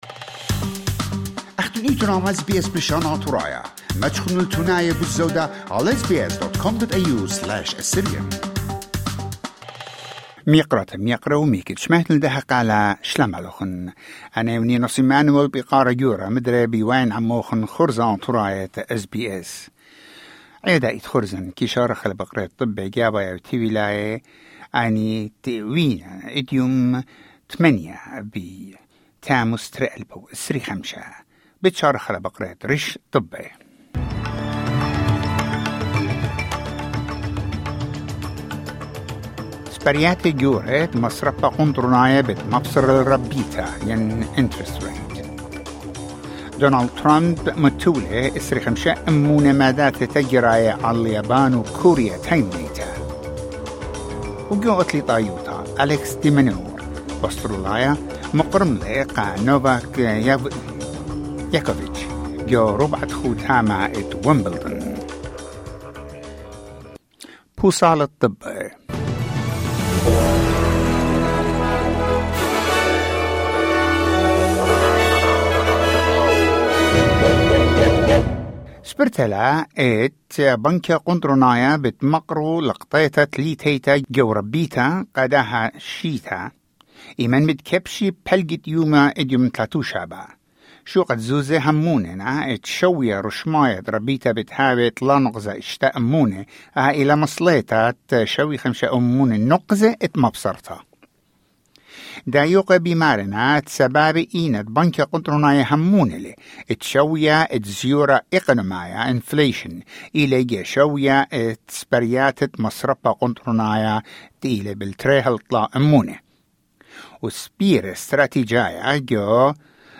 SBS Assyrian news bulletin: 8 July 2025